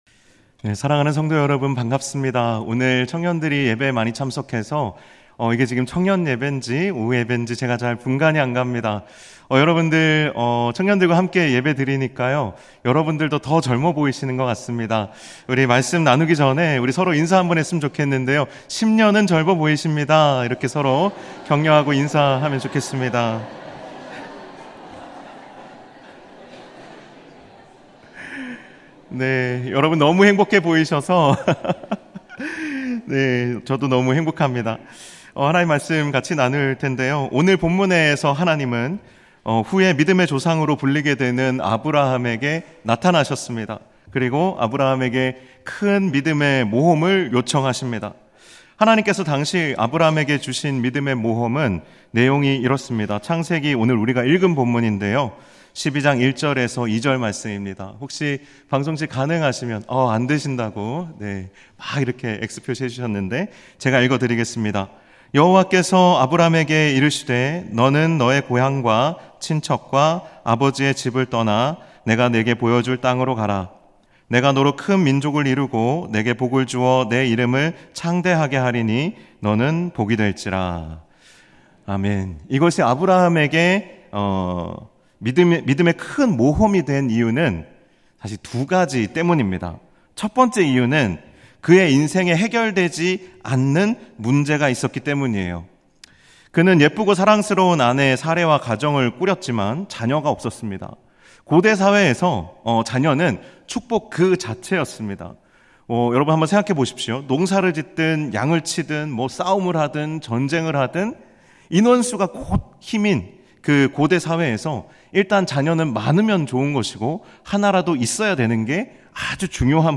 2024년 7월 21일 주일오후예배 (청년부 수련회 보고예배)
음성설교